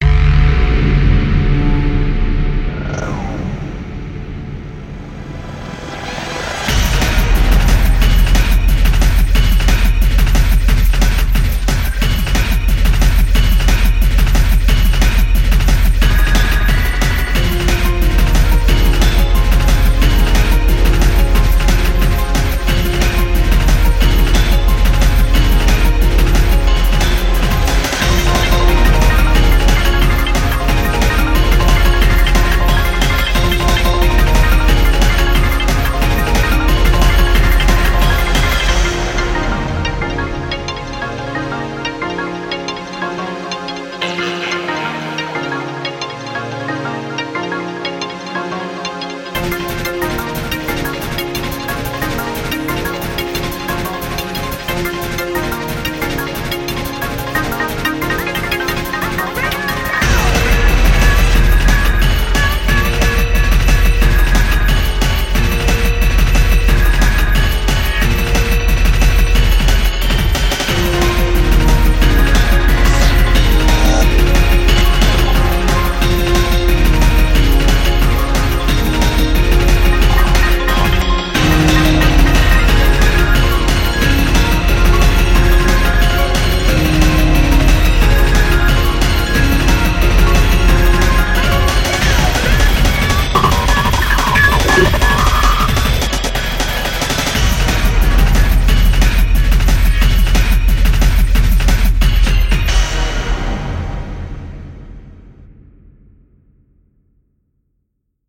BPM180
Audio QualityMusic Cut